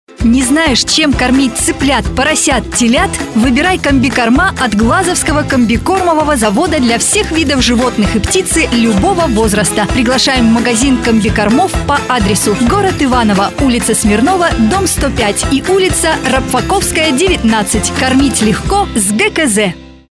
Тип рекламной конструкции: Реклама на радио